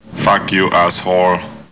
На этой странице собраны iconic soundbites из фильмов «Терминатор» — от холодного голоса T-800 до угроз Скайнета.
В коллекции только самые узнаваемые фразы с чистым звучанием.